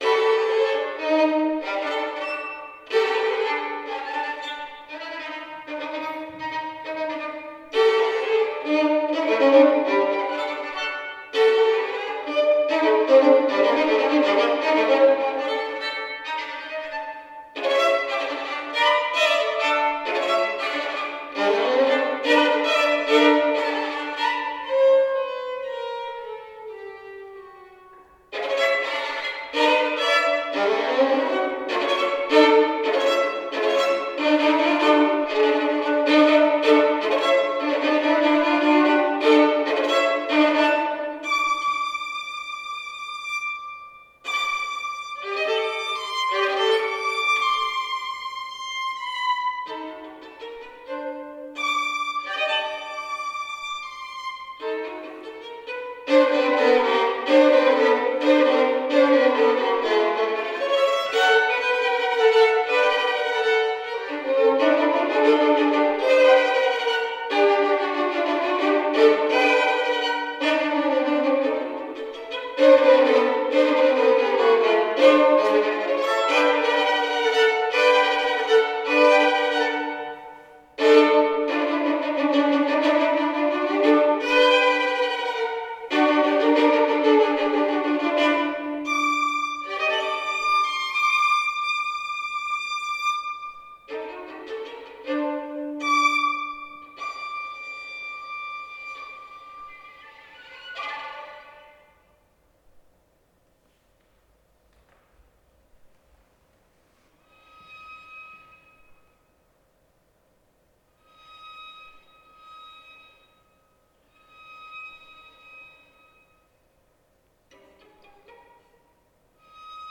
for violin (2013)
Violin